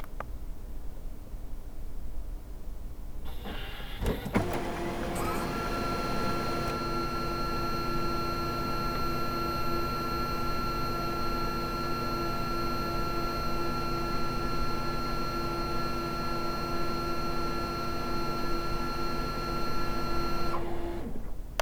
製品の稼動中に以下のファイルの音が発生しますが、正常な稼動音です。
・電源ON時
・節電復帰時
・プリンター調整中